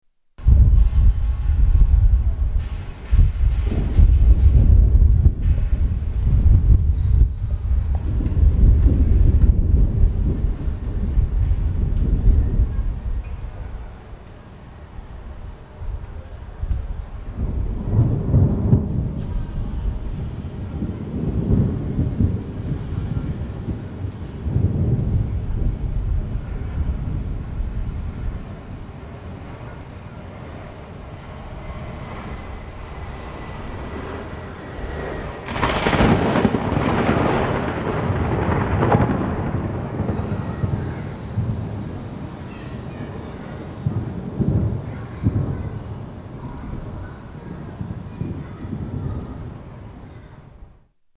Typhoon evening
Tags: Travel Taiwan Taipei Sounds of Taiwan Vacation